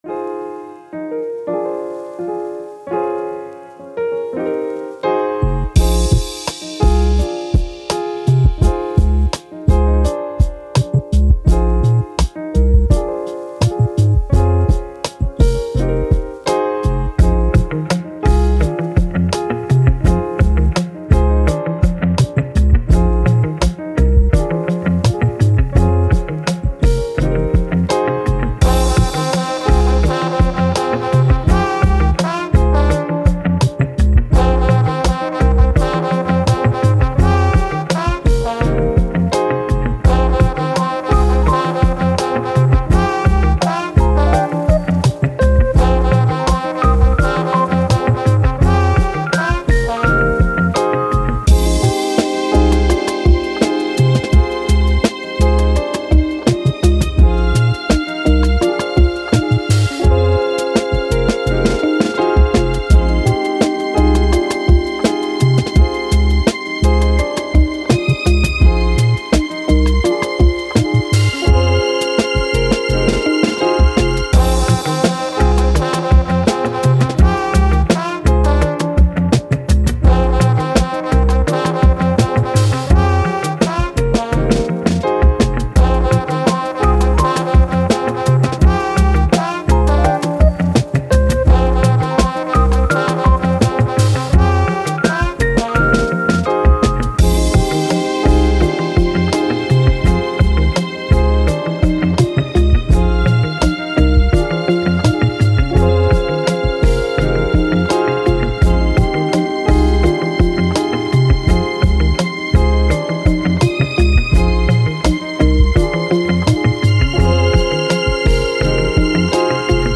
Hip Hop, Jazz, Chill, Confident